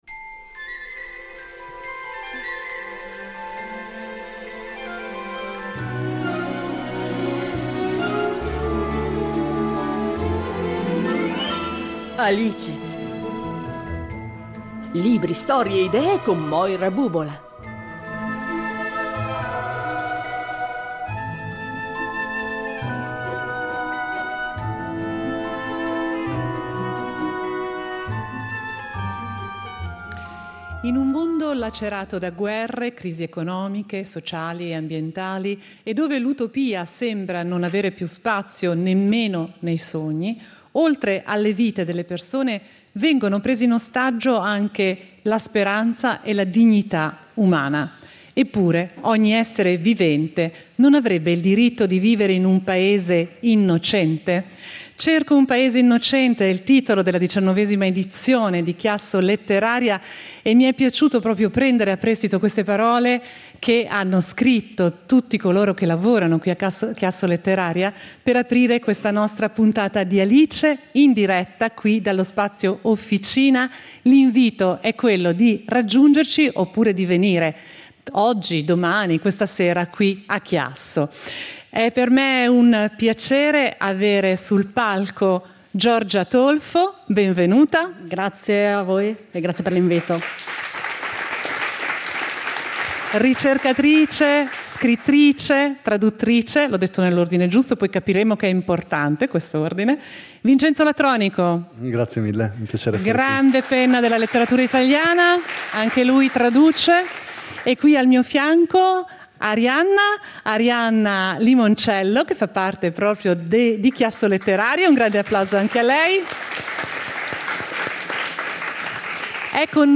In diretta dalla 19esima edizione di ChiassoLetteraria